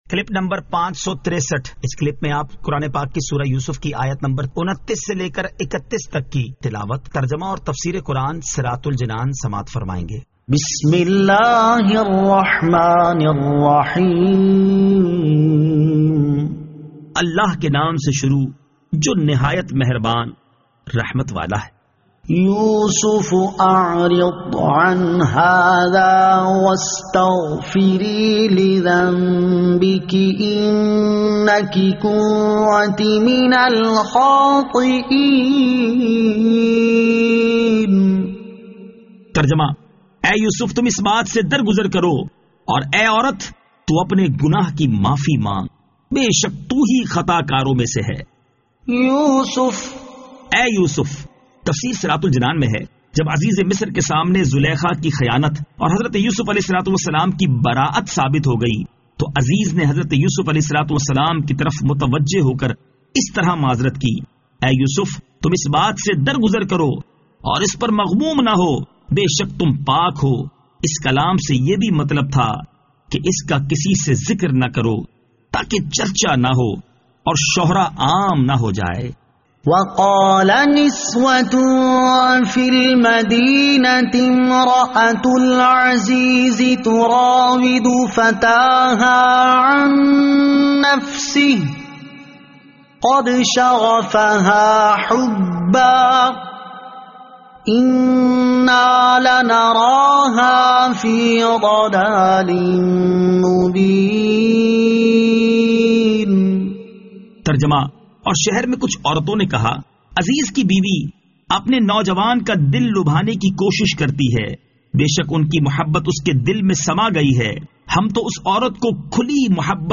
Surah Yusuf Ayat 29 To 31 Tilawat , Tarjama , Tafseer
2021 MP3 MP4 MP4 Share سُوَّرۃُ یُوْسُف آیت 29 تا 31 تلاوت ، ترجمہ ، تفسیر ۔